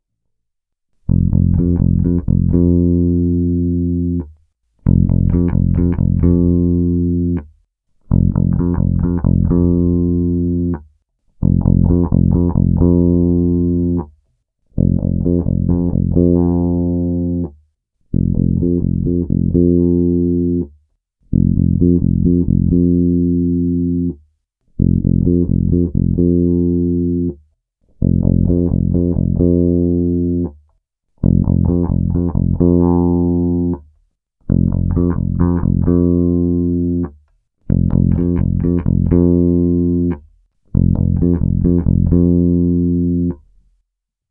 Pro lepší vnímání změny jsem nahrál rychlejší výměny jednotlivých ukázek, opět ve stejném pořadí jako výše, ale po sedmé ukázce to jede zase zpět k první poloze, tedy pasívnímu režimu, eventuálně si počítejte na prstech wink